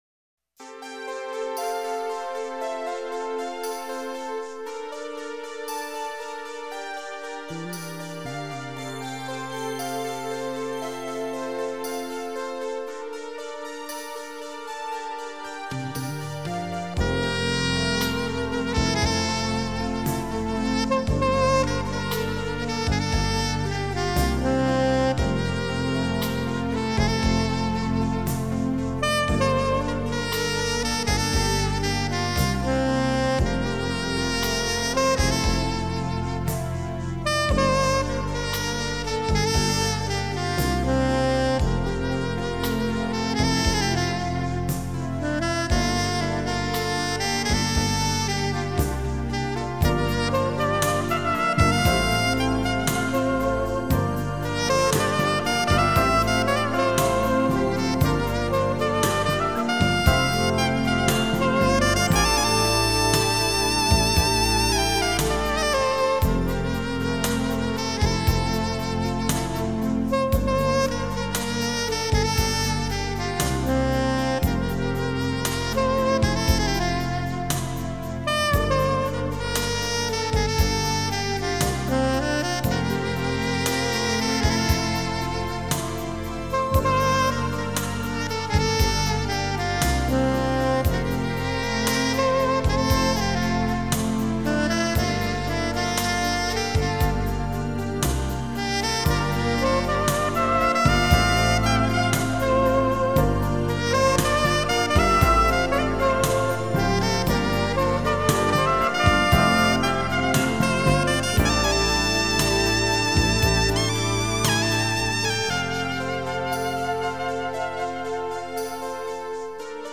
Genre: Instrumental Pop.